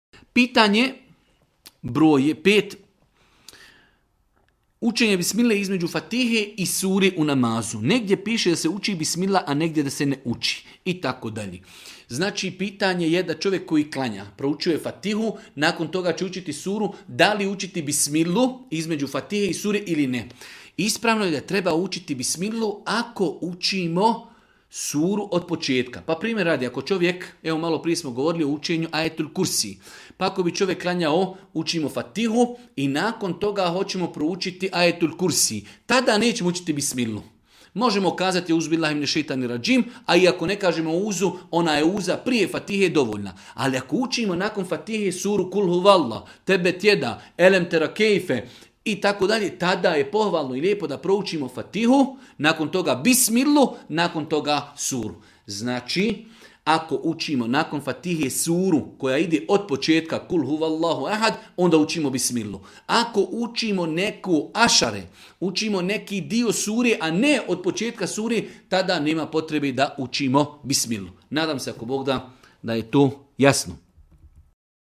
u video predavanju.